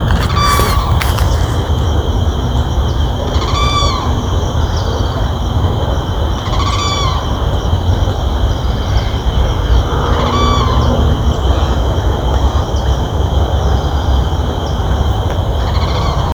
Carão (Aramus guarauna)
Nome em Inglês: Limpkin
Condição: Selvagem
Certeza: Observado, Gravado Vocal